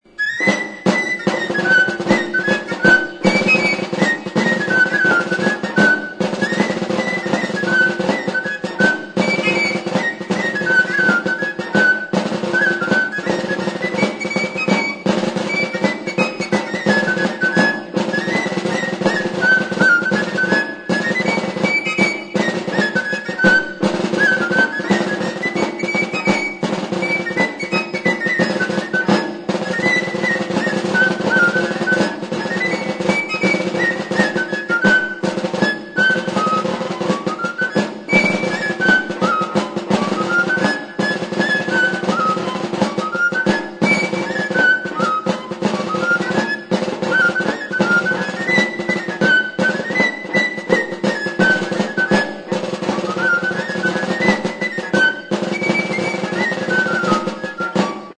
Aerófonos -> Flautas -> Recta (de una mano) + flautillas
HIRU PUNTUKOA (mutil-dantza).
TXISTUA; TXILIBITUA; SILBO
Hiru zuloko flauta zuzena da.